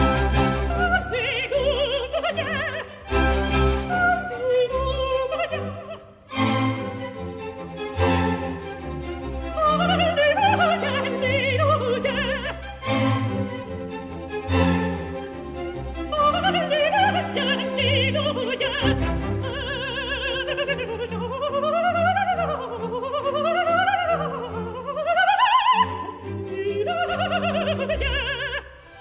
Vocalisation -Voyelle "A" -audio-Cours de chant lyrique.